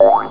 cartoon
boink1.mp3